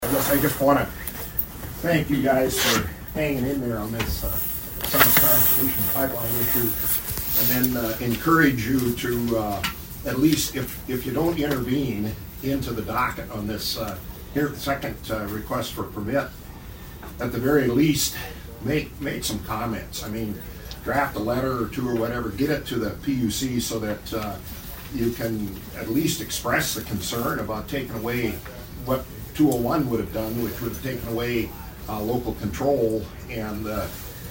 At Tuesday’s Brown County Commission, former commissioner Dennis Feickert encourage the commission to keep the pressure on the South Dakota PUC.